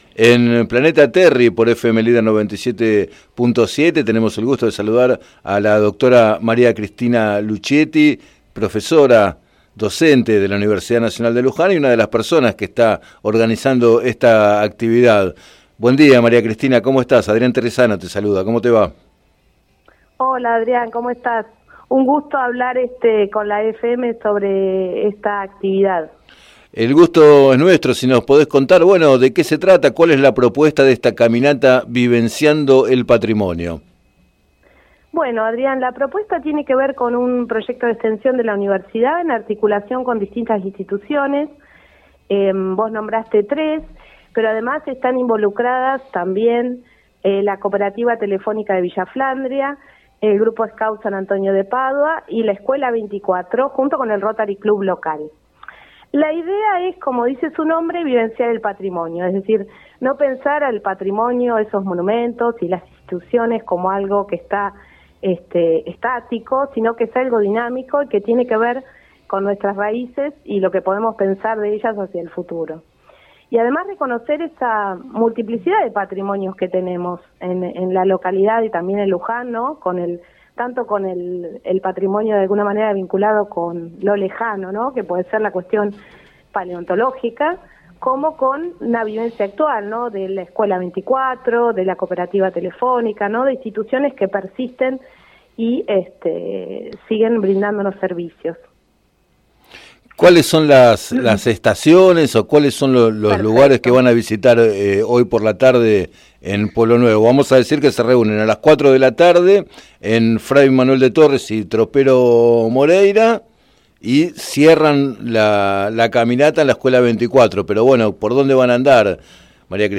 Entrevistada en el programa Planeta Terri de FM Líder 97.7